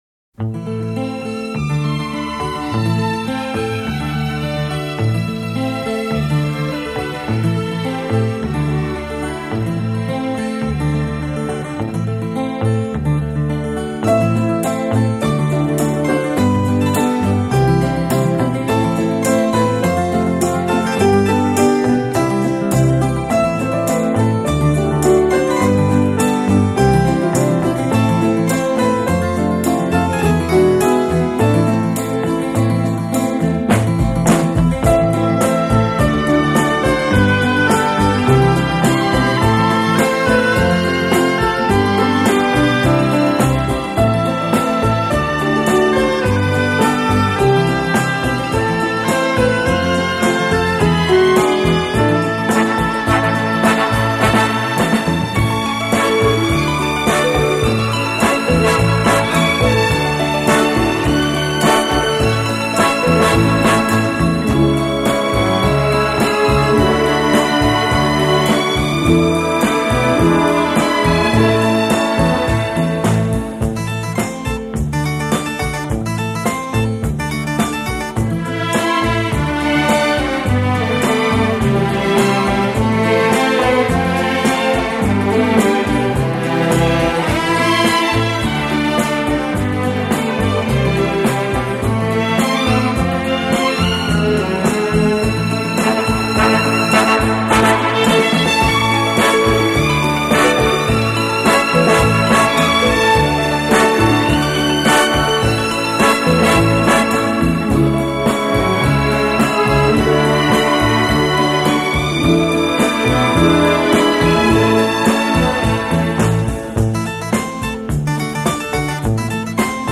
浪漫弦乐和流行风格的完美融合 精装6CD超值价答谢乐迷